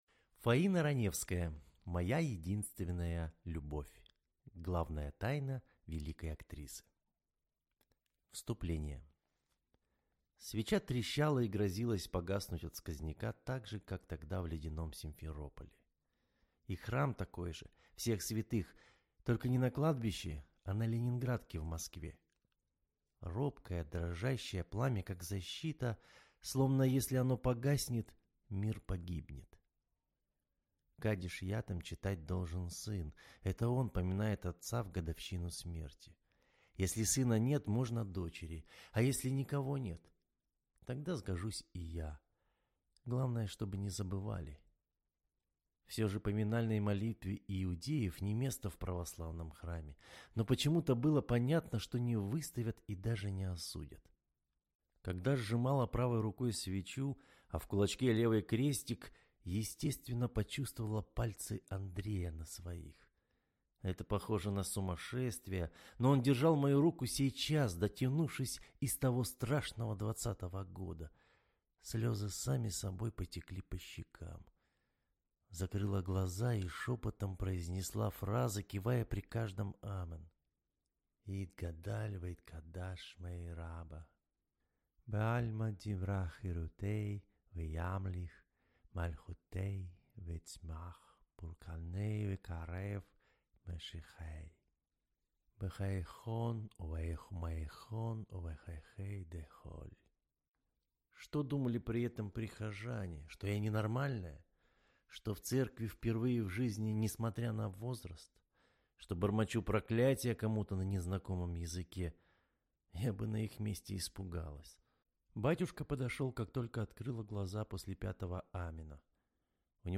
Аудиокнига «Моя единственная любовь». Главная тайна великой актрисы | Библиотека аудиокниг